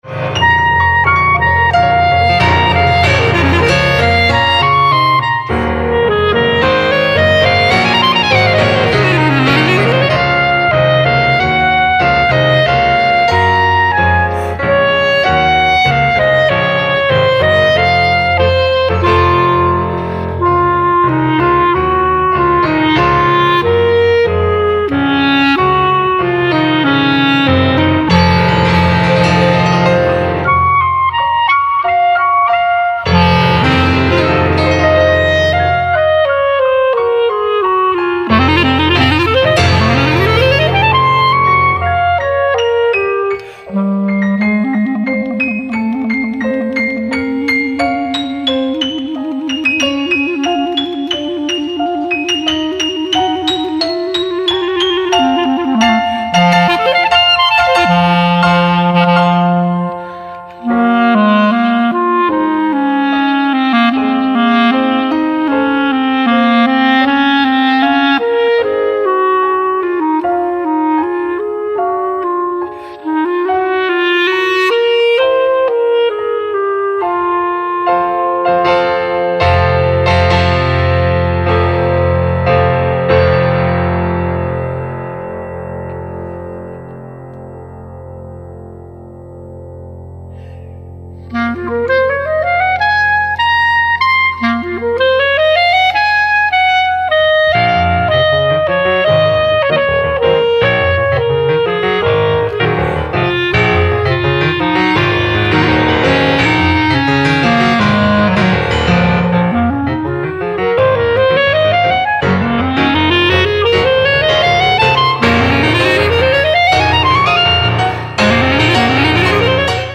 Live au FMPM 2006: